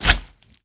sounds / mob / slime / big4.ogg